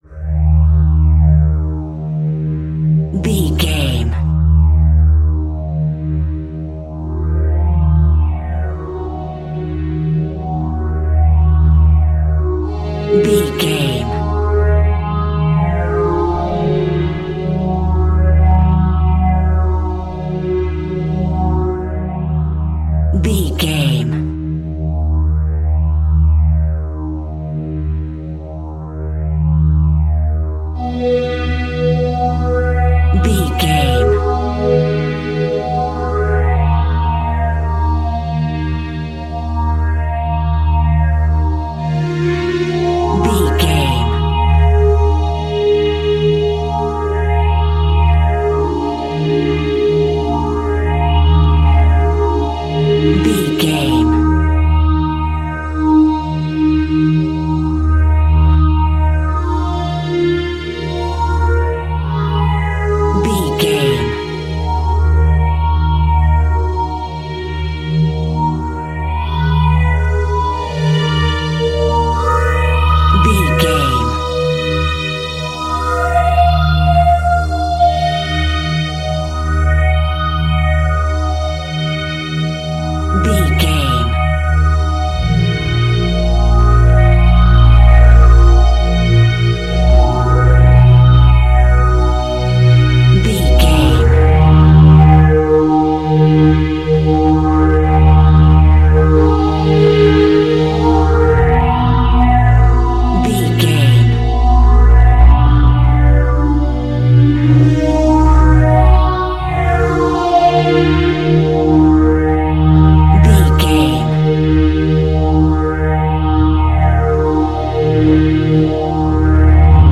Aeolian/Minor
E♭
Slow
scary
ominous
dark
haunting
eerie
synthesizer
horror
viola